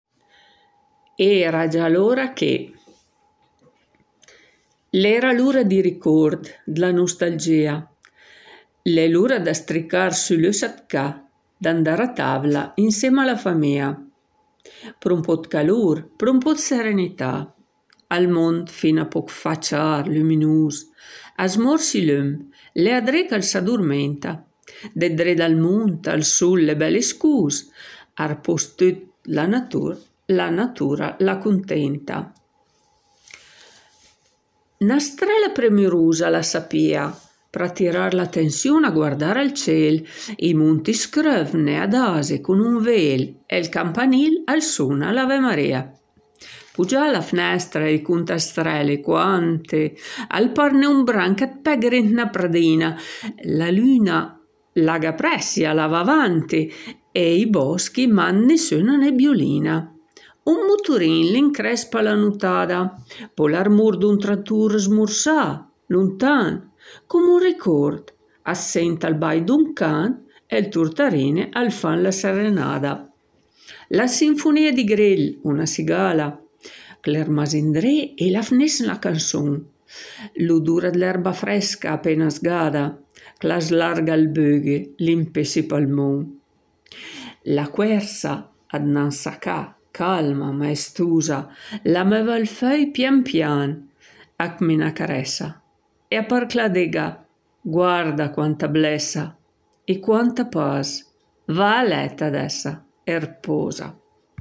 La poesia Era già l’ora che  è letta